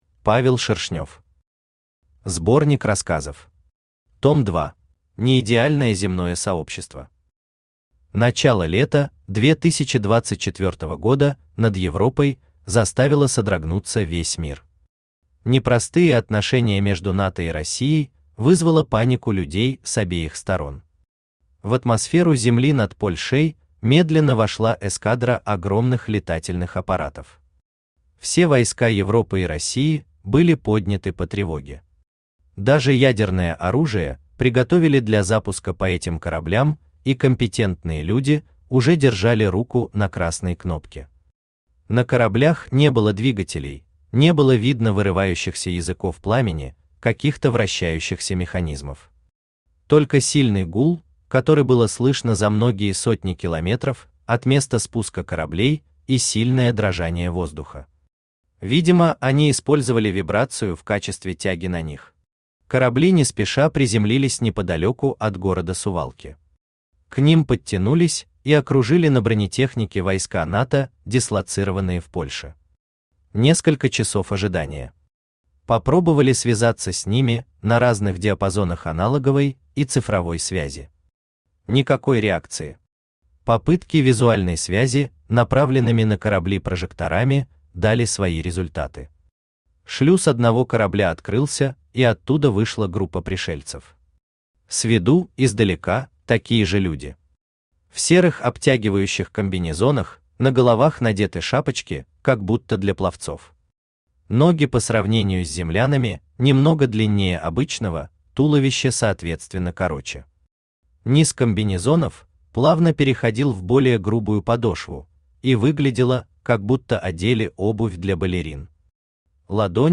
Аудиокнига Особенные | Библиотека аудиокниг
Aудиокнига Особенные Автор Павел Валерьевич Шершнёв Читает аудиокнигу Авточтец ЛитРес. Прослушать и бесплатно скачать фрагмент аудиокниги